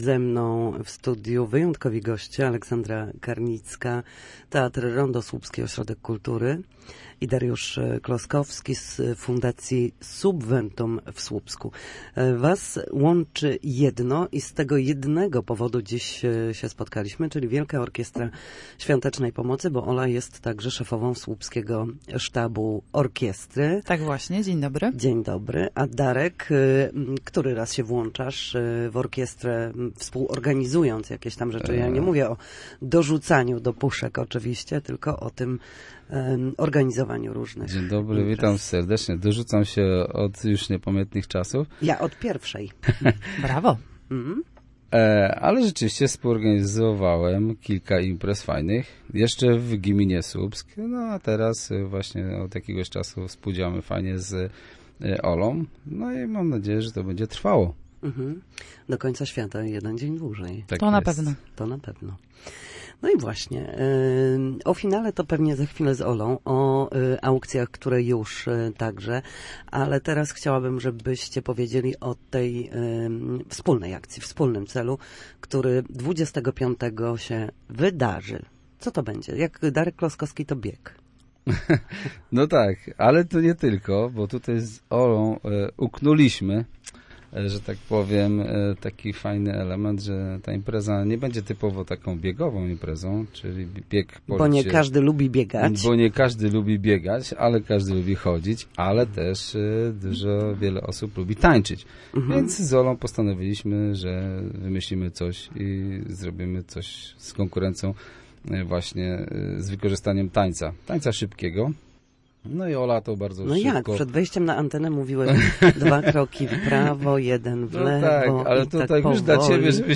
O tym na naszej antenie mówili